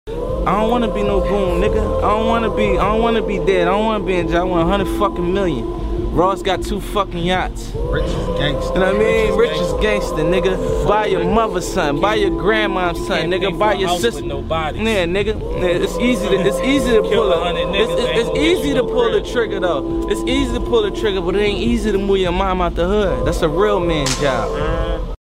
Meek Mill's Speech Against The sound effects free download